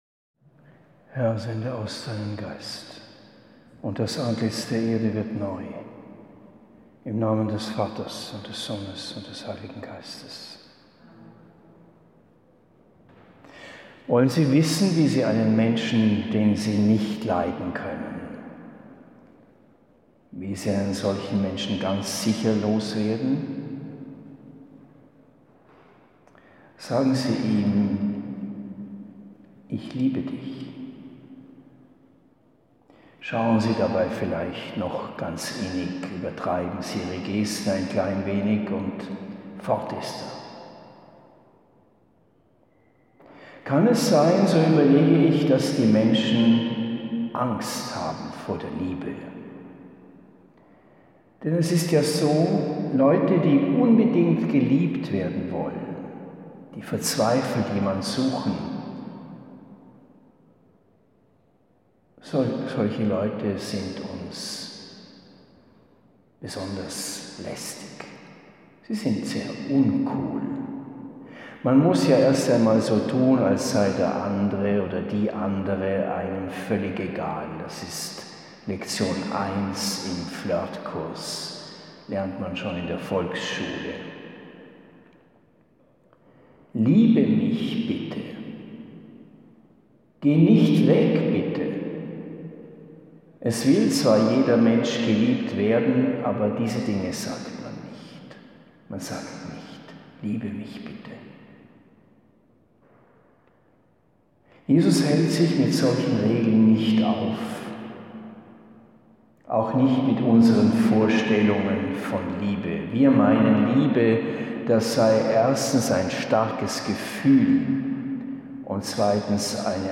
Zum mündlichen Vortrag bestimmt, verzichtet dieser Text auf Quellenangaben.